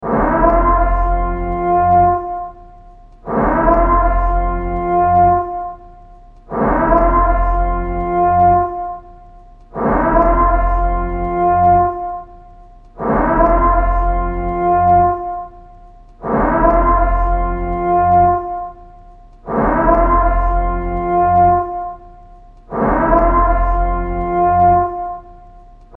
The-purge-siren.mp3